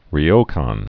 (rē-ōkän, ryō-)